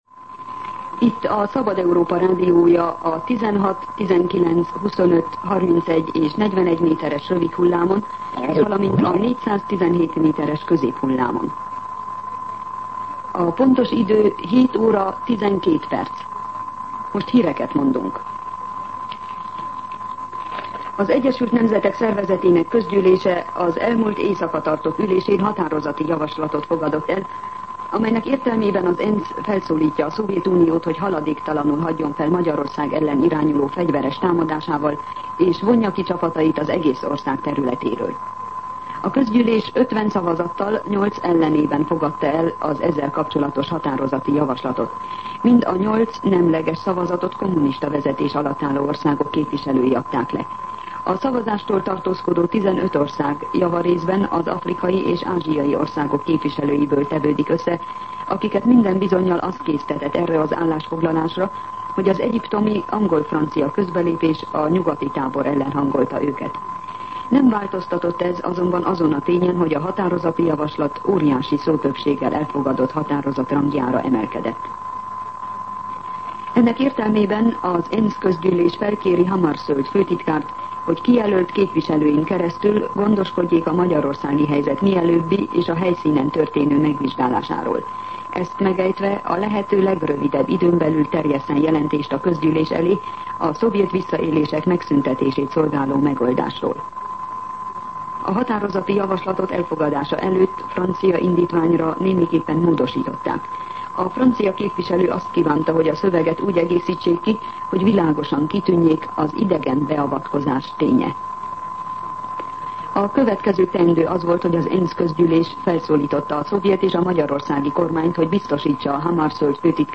07:12 óra. Hírszolgálat